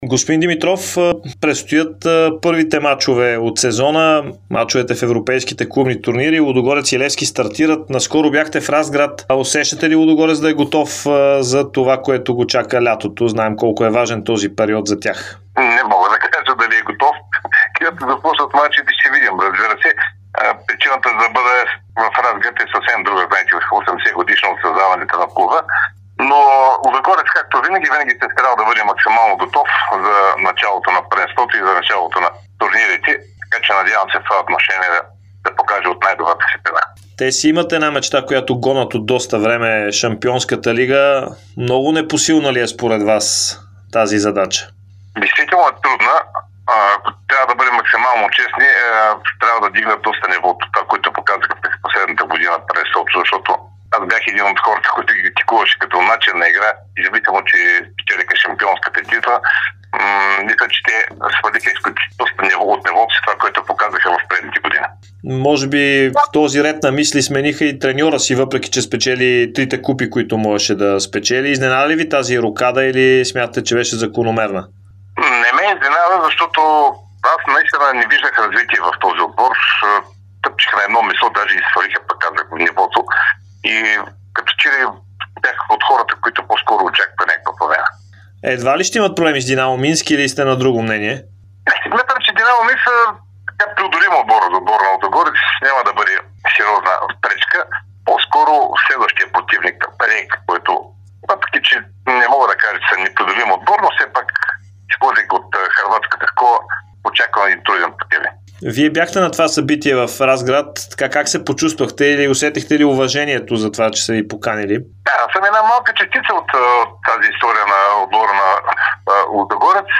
Димитър Димитров - Херо даде специално интервю пред Дарик радио и dsport, в което говори за състоянието на Лудогорец в момента. Той сподели, че не е изненадан от треньорската рокада при "орлите", защото не виждал развитие в тима.